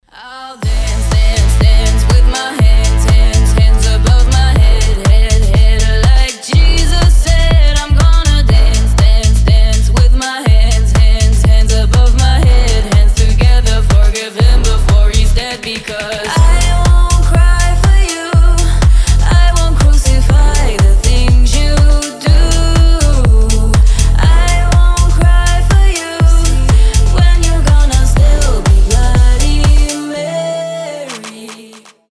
• Качество: 320, Stereo
remix
Tech House
Cover Mix